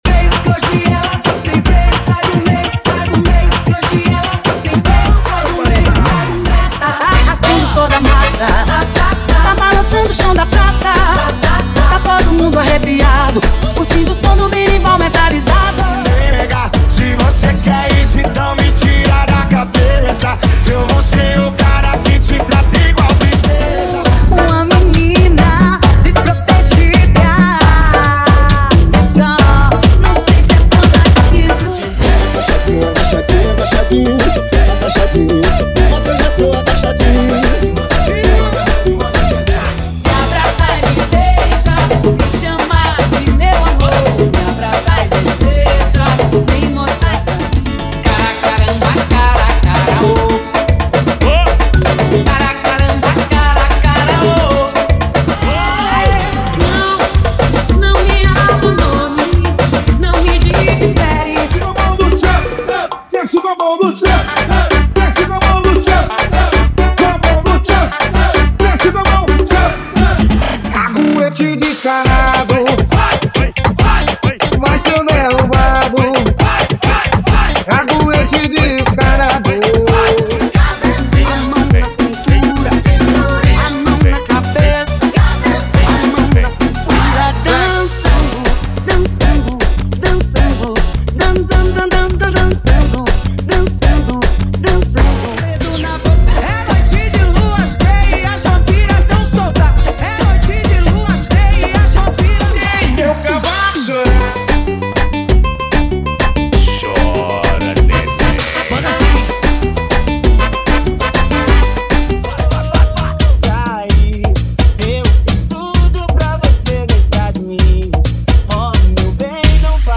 • 🔥 Carna Funk
✔ Músicas sem vinhetas